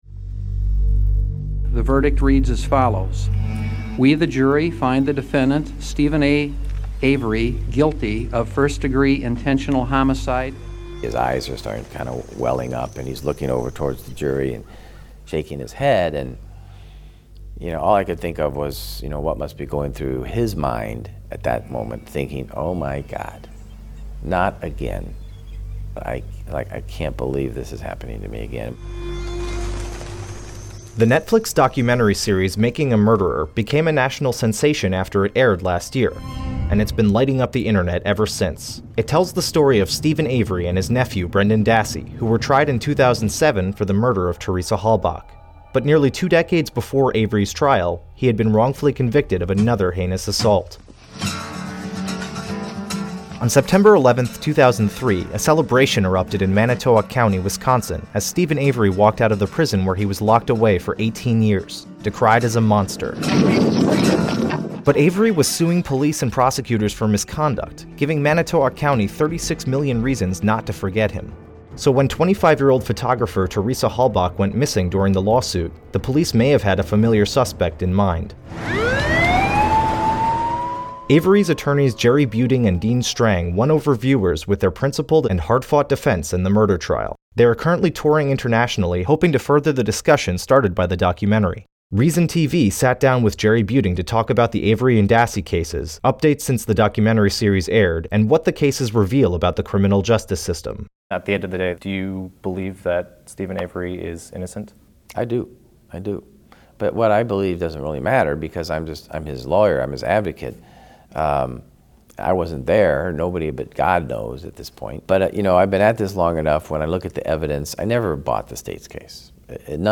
Reason TV sits down with Steven Avery's defense attorney.